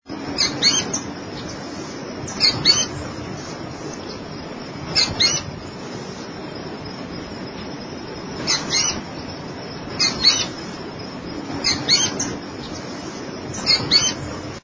Sooty Fronted Spinetail
voz-pijuí-frente-grisf.mp3